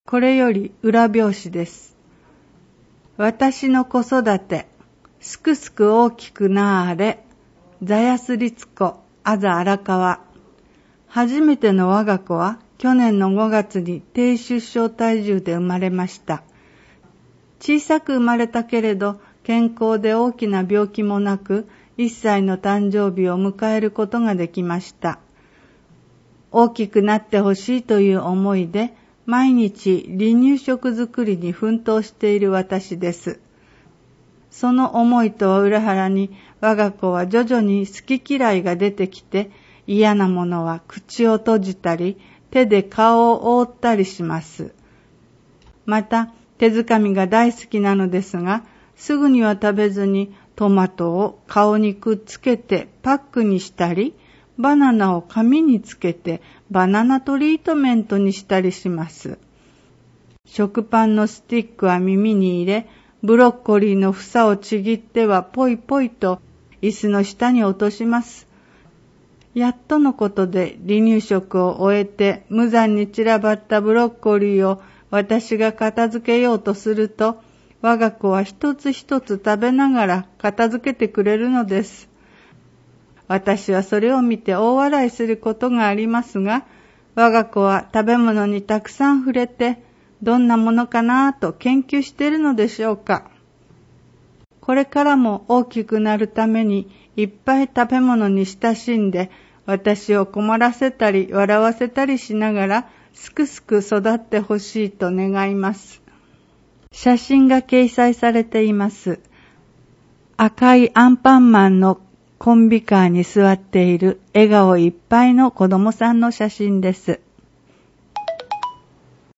以下は音訳ファイルです